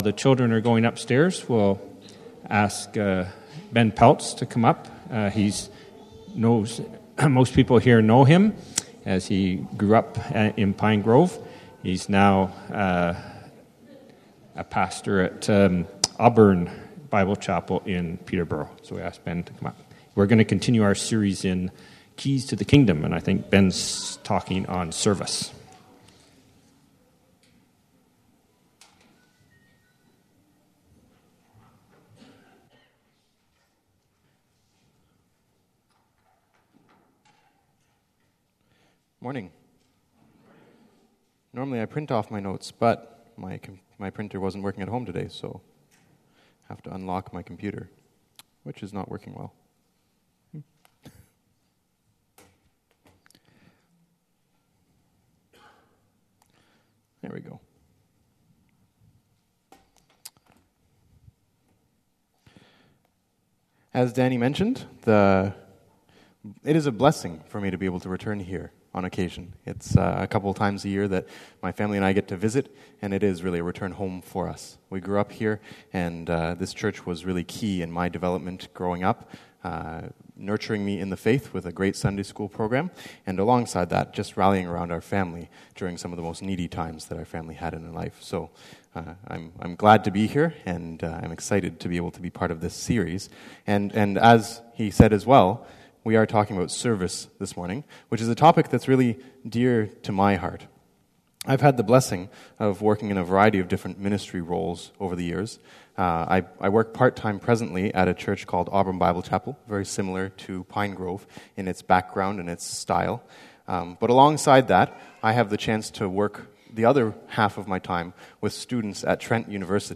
PG Sermons